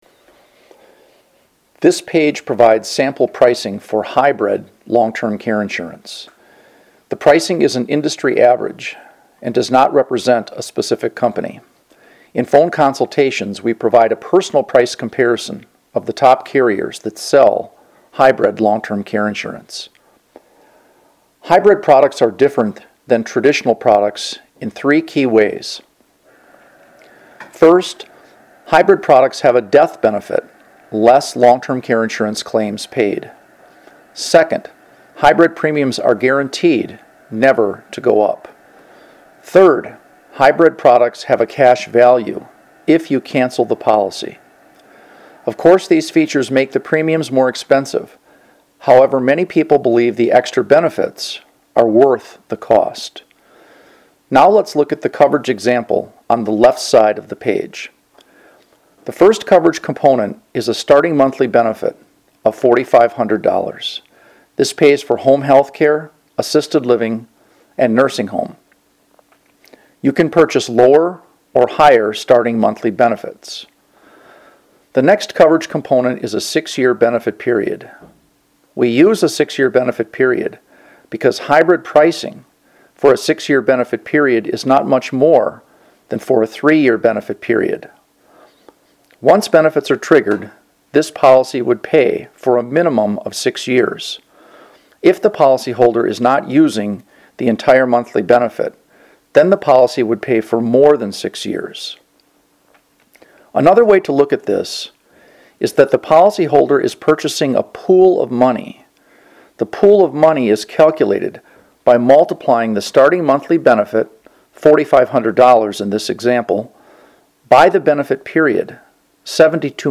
Listen to a narrated guide for the chart above
hybrid-pricing-audio-guide.mp3